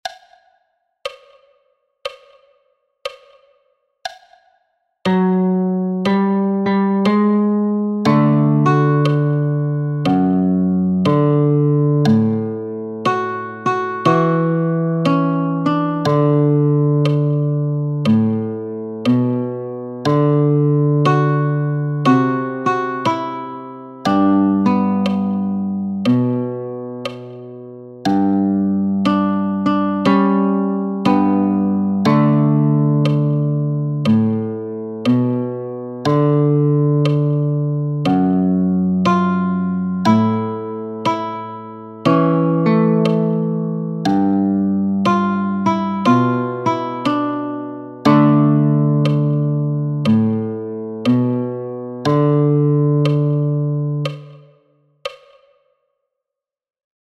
Traditionelle Blues Songs + Sounds